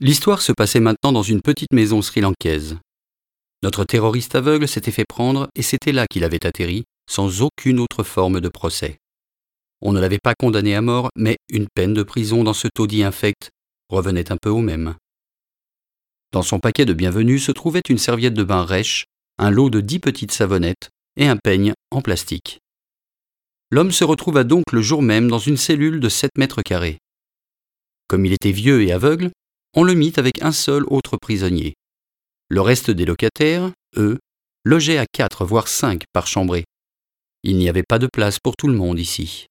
French native middle age male and warm voice.
Sprechprobe: Sonstiges (Muttersprache):